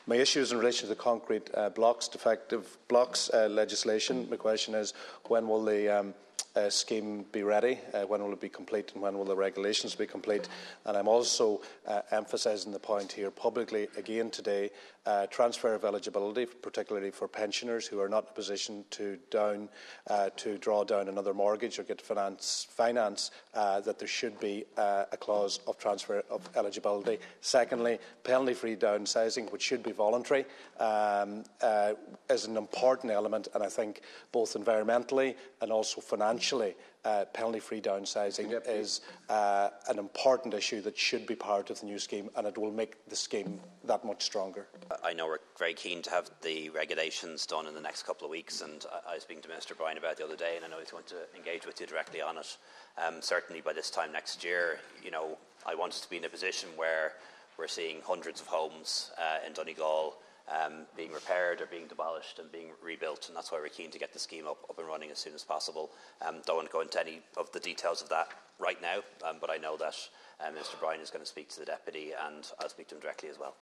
Dail hears call for penalty free downsizing to be included in enhanced redress scheme
Donegal Deputy Joe McHugh allowing homeowners to downsize as part of the scheme would be hugely beneficial: